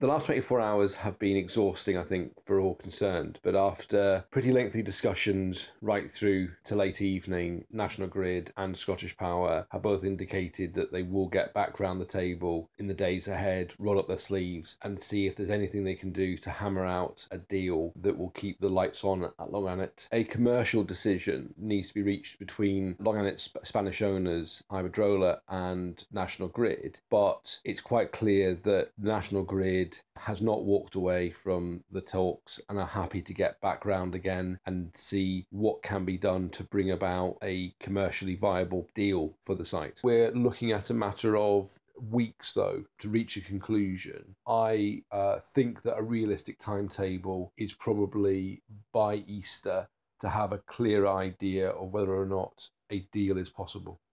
Mr Docherty says an agreement could be reached relatively soon: